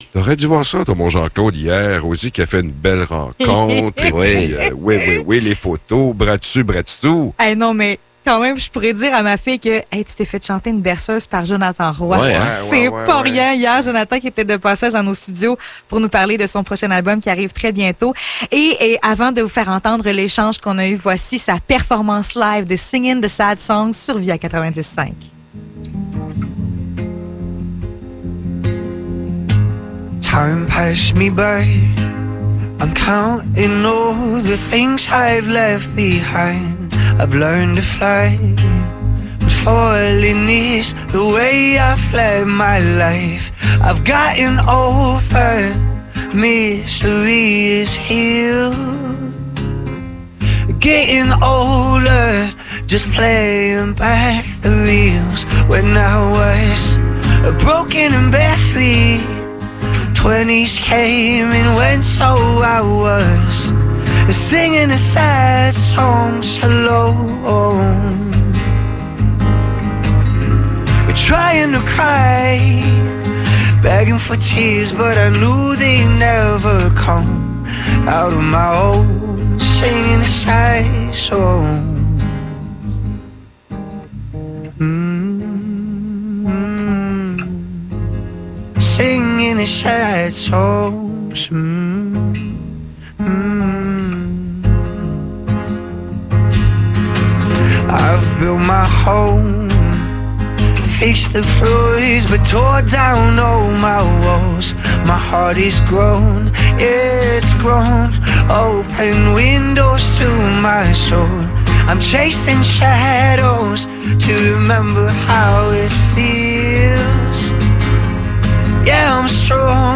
Entrevue et performance de Jonathan Roy
Jonathan Roy est passé dans nos studios pour nous parler de son nouvel album et nous chanter une chanson!